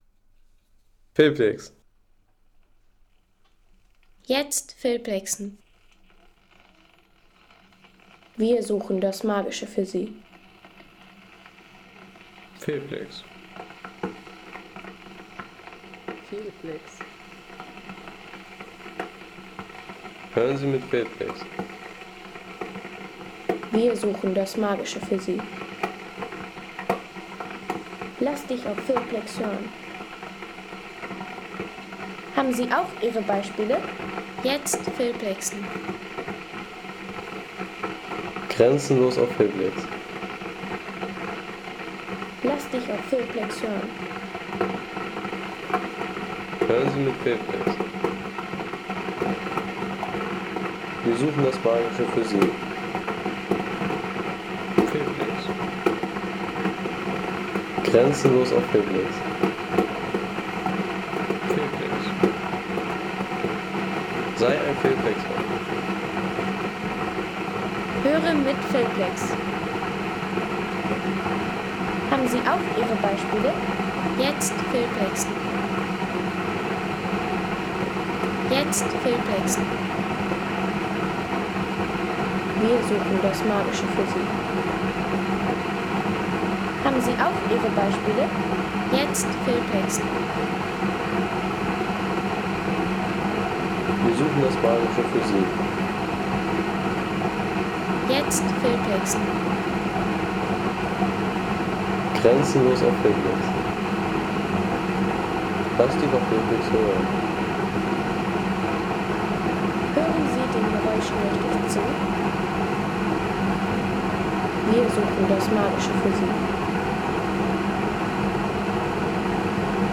Wasserkocher / Wasser kochen
Der Kenwood SKM 030 kMix – Eleganz und Effizienz in Ihrer Küche.